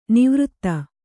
♪ nivřtta